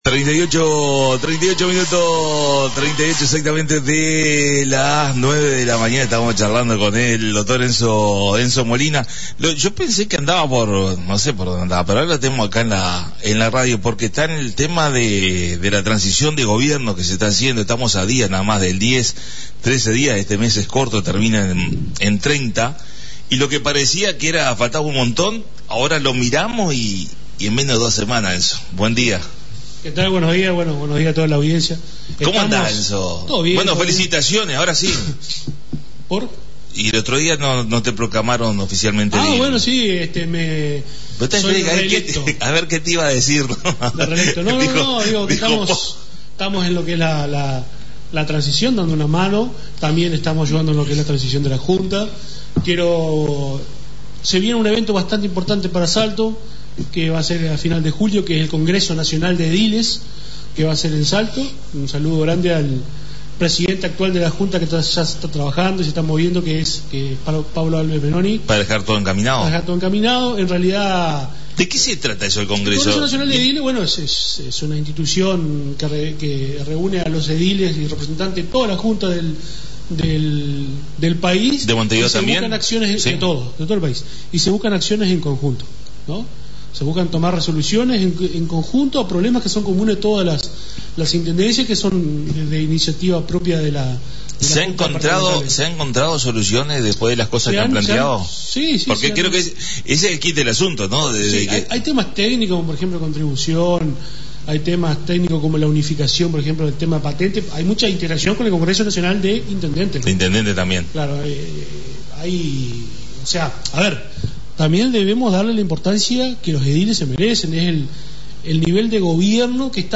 La visita a la Radio del Dr Enzo Molina Edil Electo Lista 404 Partido Nacional.